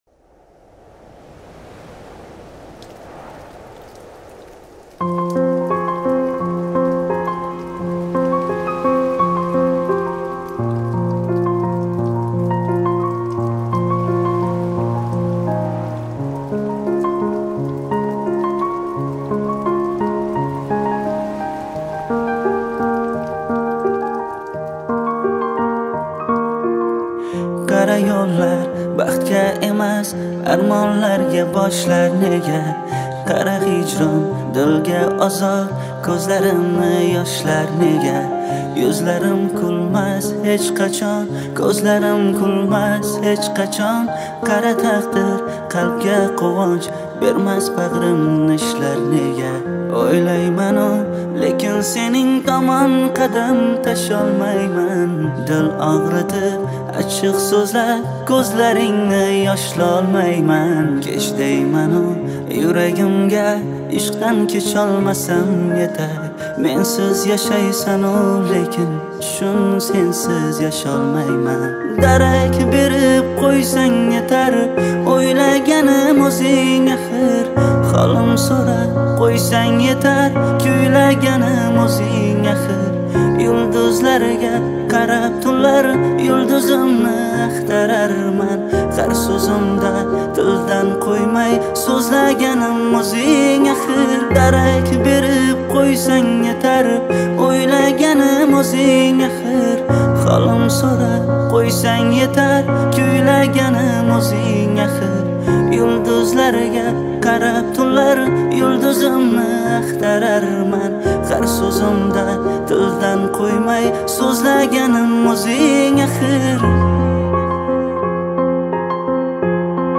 Узбекский песни